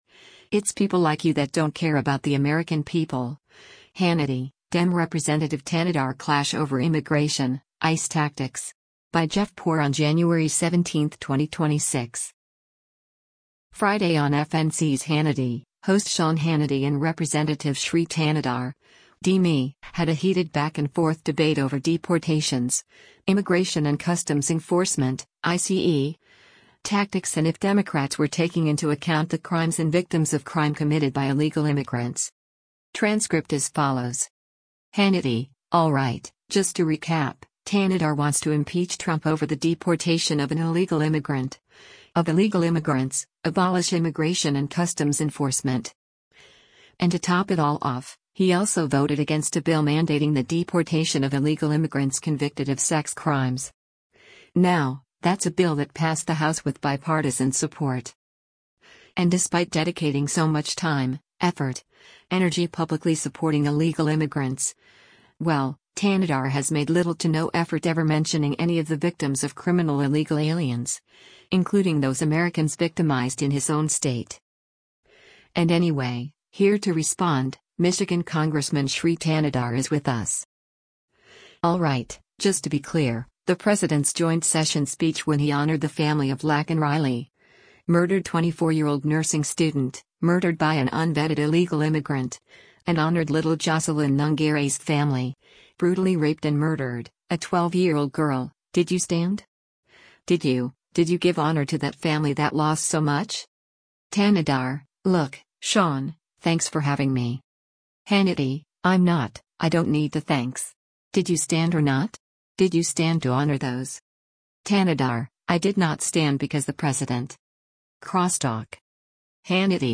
Friday on FNC’s “Hannity,” host Sean Hannity and Rep. Shri Thanedar (D-MI) had a heated back-and-forth debate over deportations, Immigration and Customs Enforcement (ICE) tactics and if Democrats were taking into account the crimes and victims of crime committed by illegal immigrants.